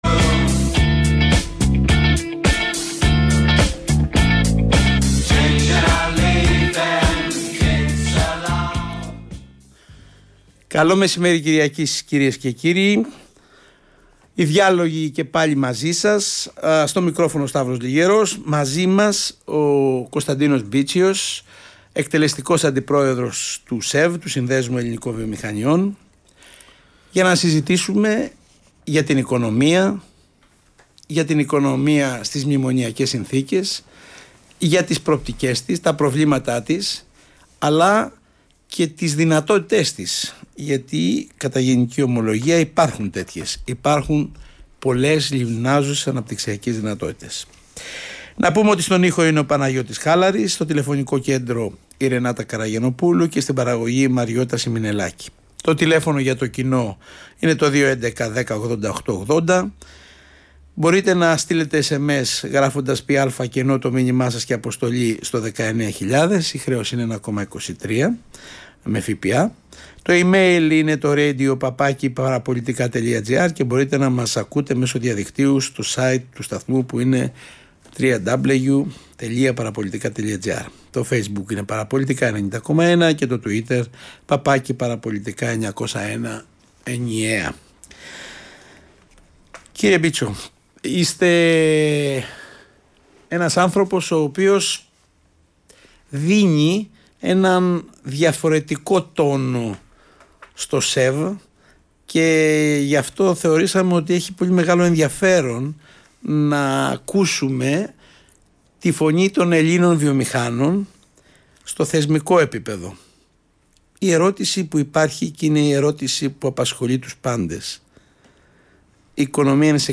μιλά στον Ρ/Σ ΠΑΡΑΠΟΛΙΤΙΚΑ FM